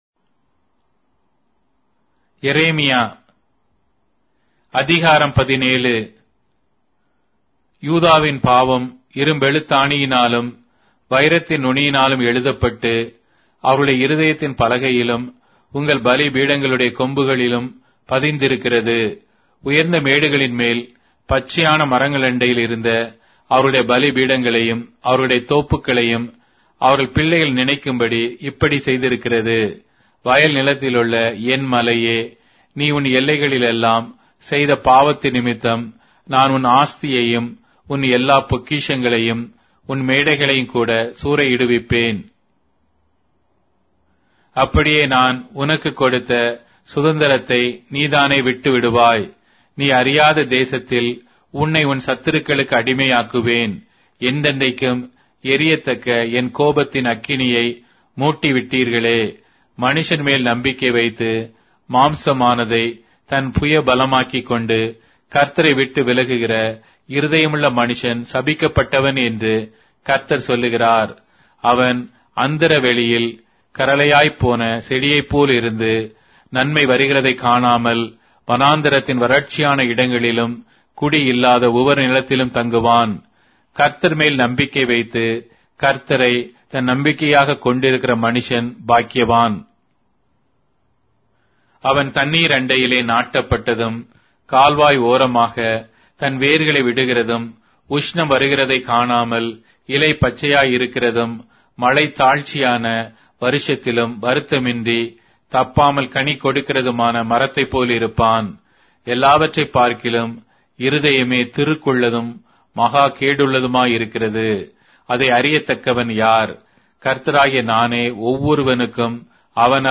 Tamil Audio Bible - Jeremiah 24 in Kjv bible version